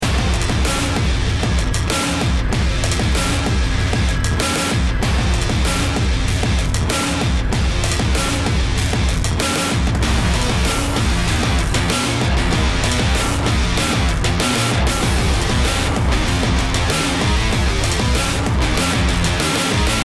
BPM 96